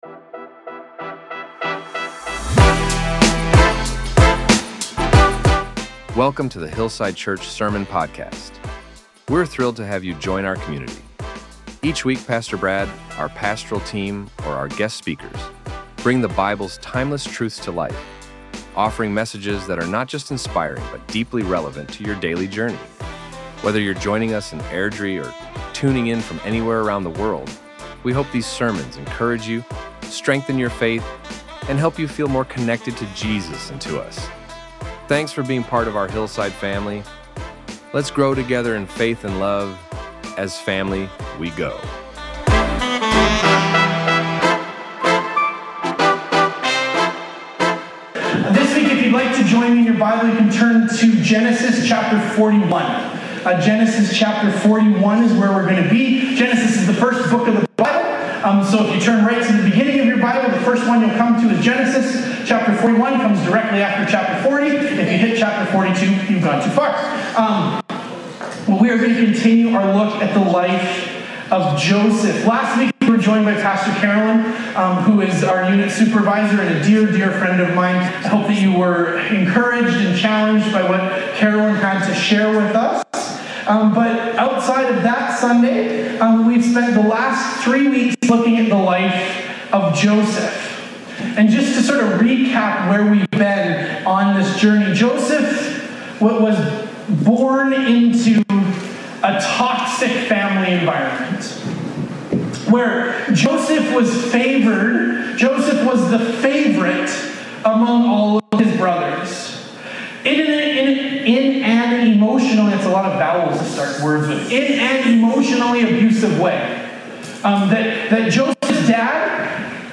There were some quality issues with the recording this week. But it is resolved a couple of minutes into the sermon.